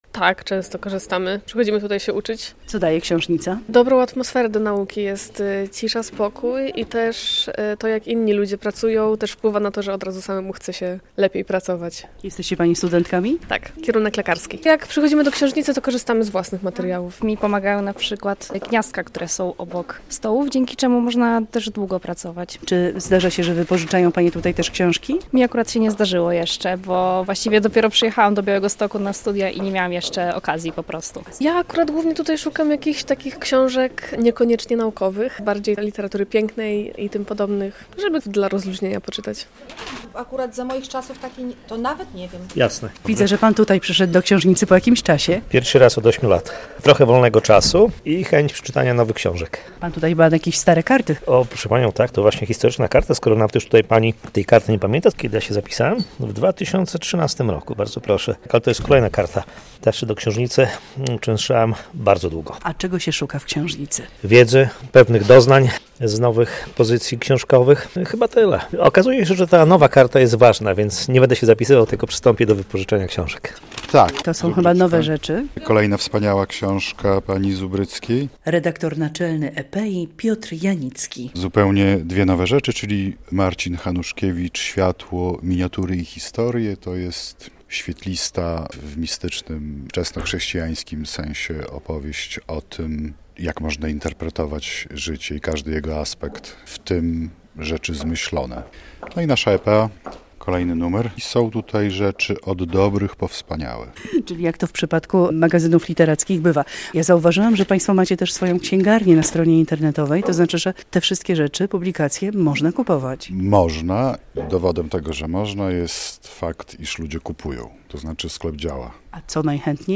Z czytelnikami Książnicy Podlaskiej rozmawiała